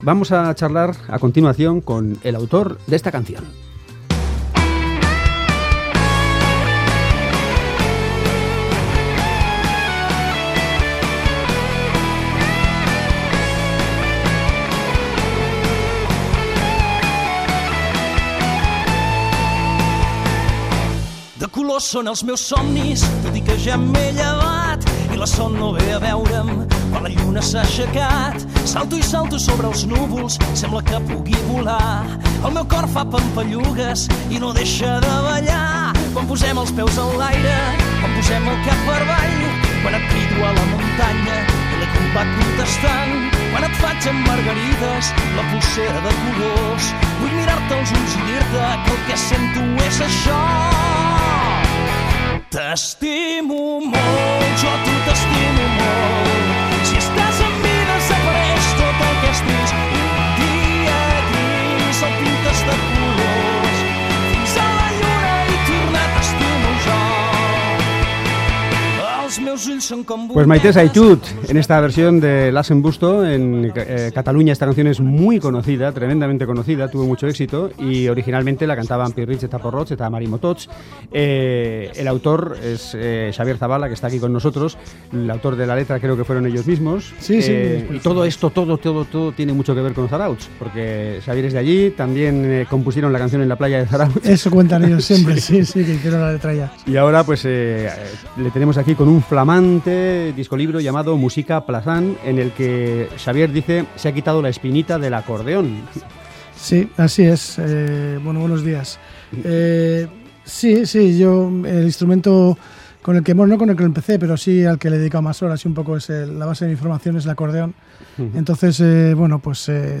Acordeón.